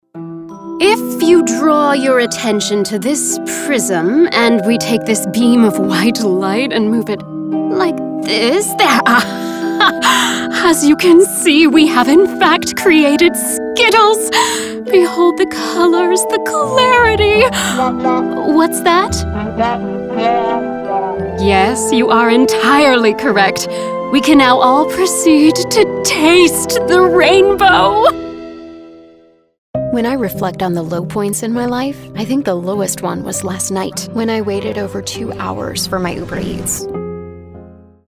Adult, Young Adult
Has Own Studio
character - us accent